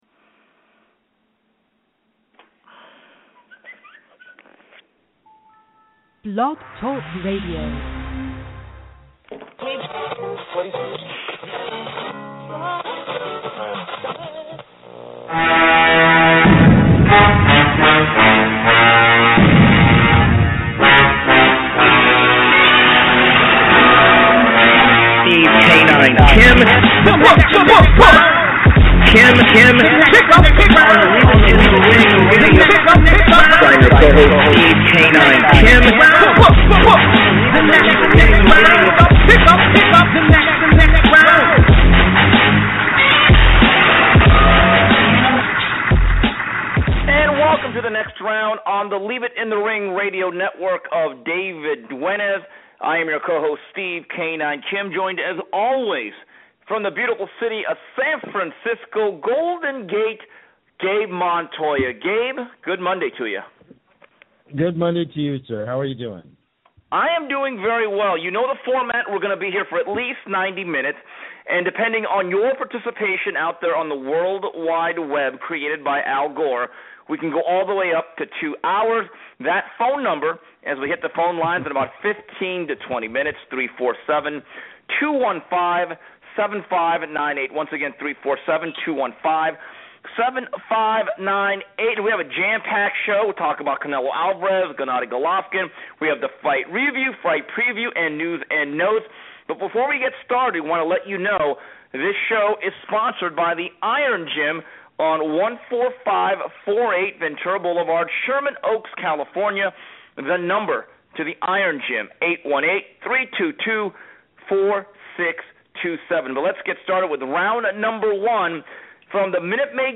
Plus, they preview this weekend's HBO-televised showdown between Middleweight Champion Gennady Golovkin and Willie Monroe, Jr. And as always, news, notes and your calls.